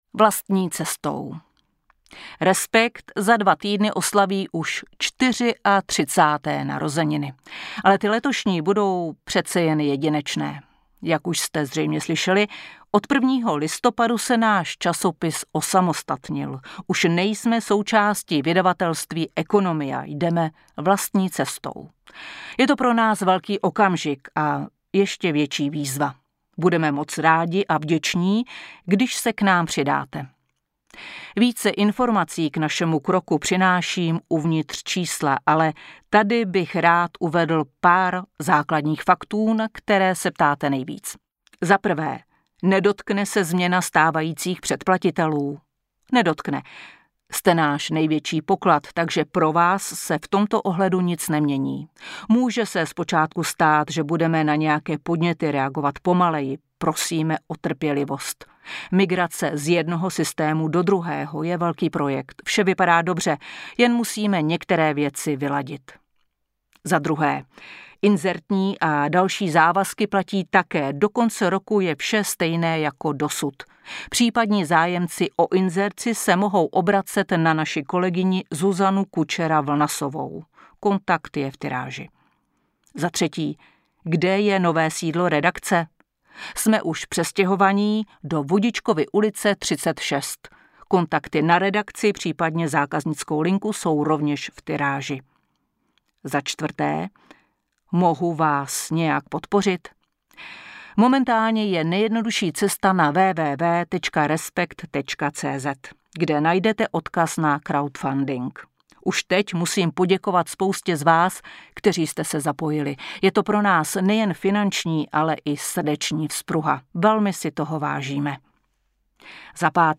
Minulý týden Nahráno ve společnosti 5Guests.
Respekt 2/2021 – Audiotéka ve spolupráci s českým týdeníkem Respekt představuje Respekt v audioverzi.